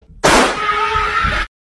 Tiếng Điện Nổ và tiếng Hét meme
Thể loại: Âm thanh meme Việt Nam
Description: Một hiệu ứng âm thanh sửa điện, cắm ổ cắm điện đặc sắc kết hợp giữa tiếng điện giật mạnh mẽ và tiếng hét kinh hoàng của đàn ông, tạo nên cảm giác giật mình, hài hước nhưng cực kỳ kịch tính.
tieng-dien-no-va-tieng-het-meme-www_tiengdong_com.mp3